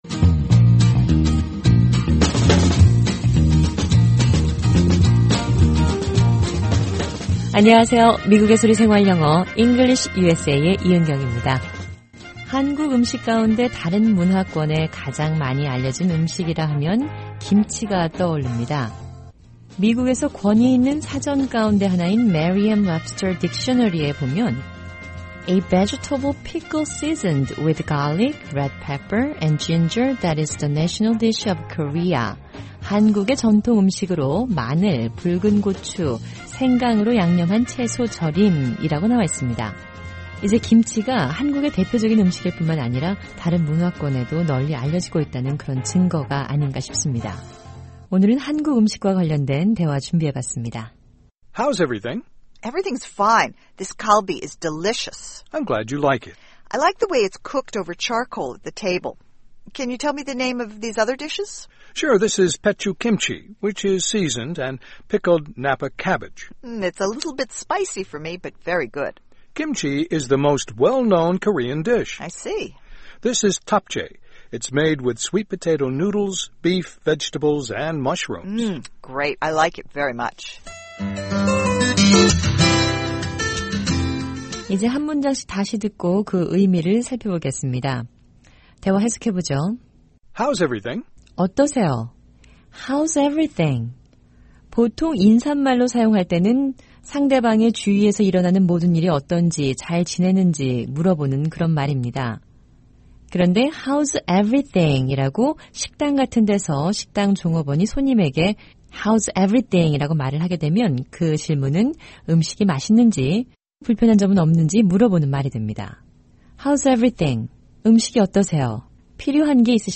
일상생활에서 사용하는 영어 표현을 배우는 'VOA 현장영어', 오늘은 한국 음식을 소개하는 대화 들어보겠습니다.